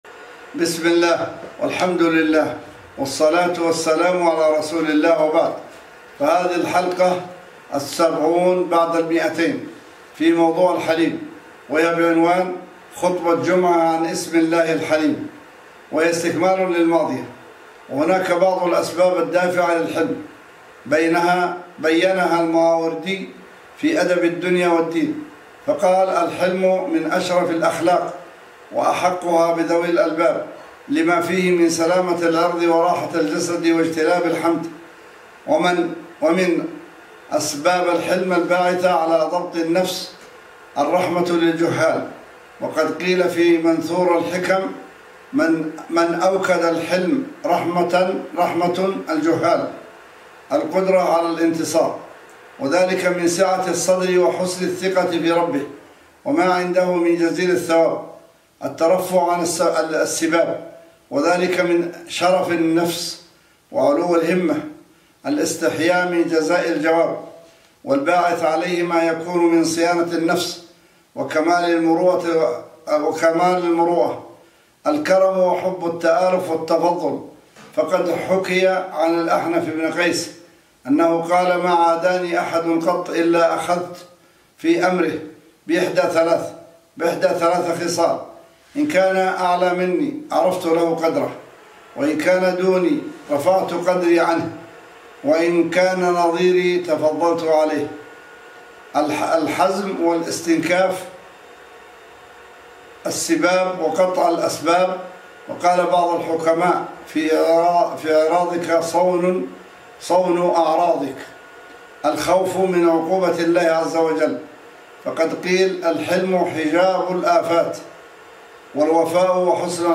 بعنوان: خطبة جمعة عن اسم الله (الحَلِيم) :